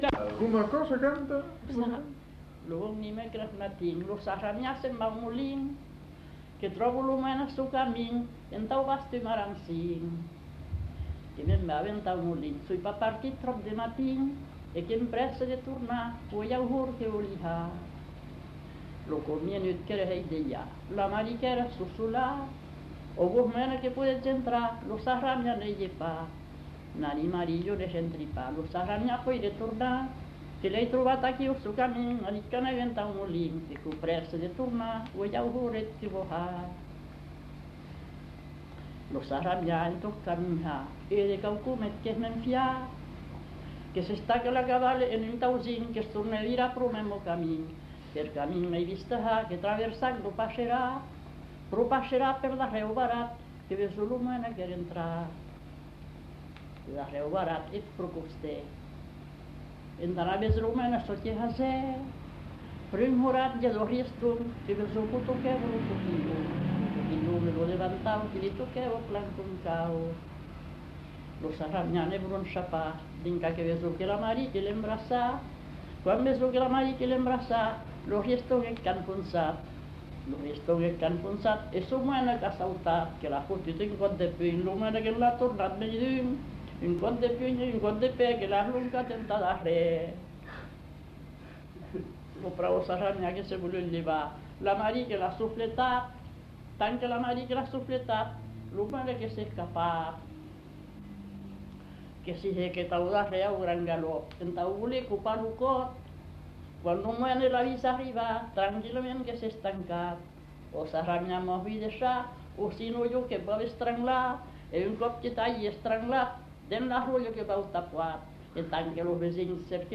Lieu : Landes
Genre : chant
Effectif : 1
Type de voix : voix de femme
Production du son : chanté